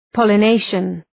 Προφορά
{,pɒlə’neıʃən}